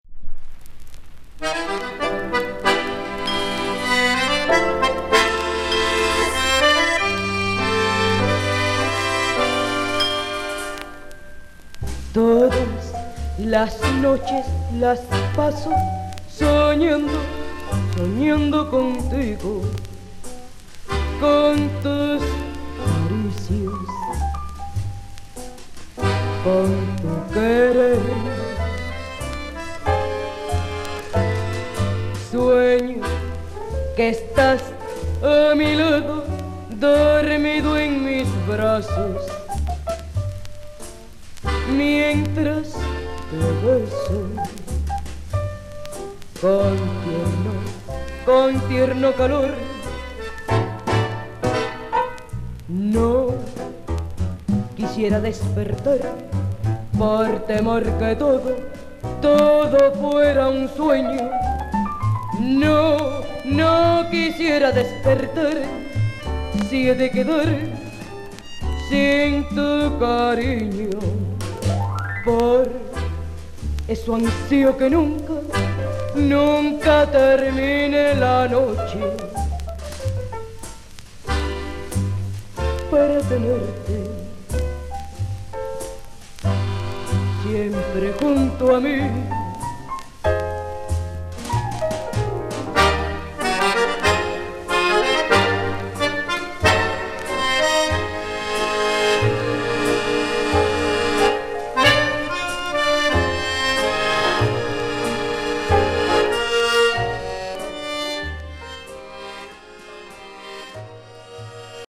1950～1960年代に活躍したキューバの女性ボーカリスト